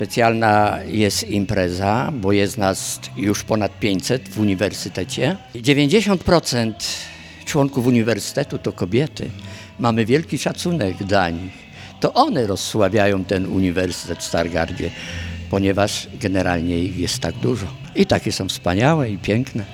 Wyjątkowo skocznie i wesoło przebiegały dziś obchody Dnia Kobiet organizowane przez stargardzki Uniwersytet Trzeciego Wieku’.